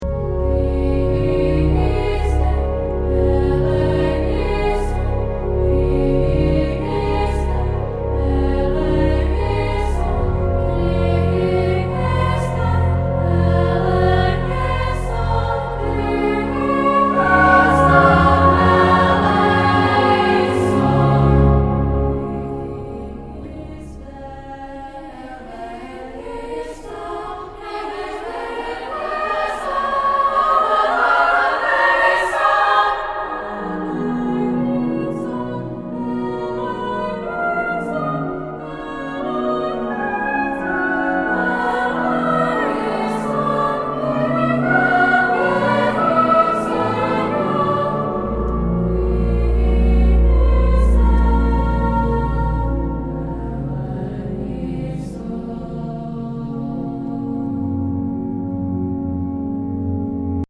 orch. verze 2